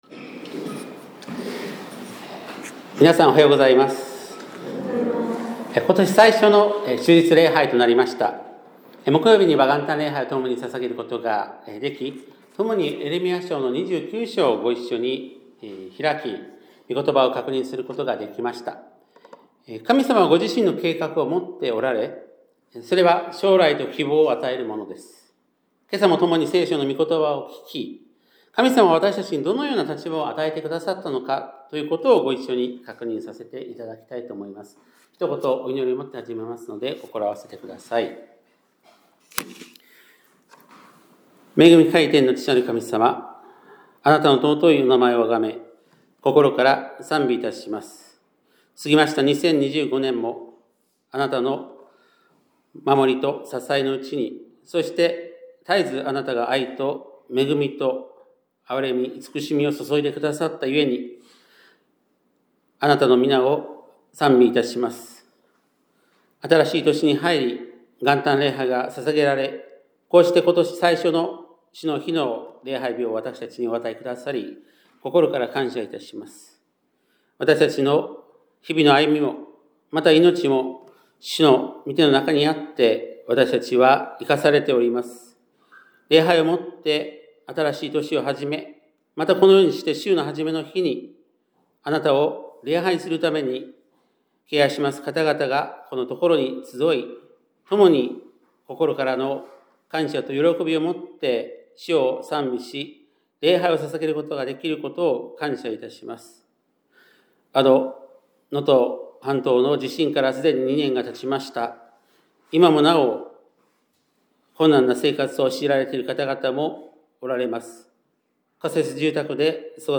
2026年1月4日（日）礼拝メッセージ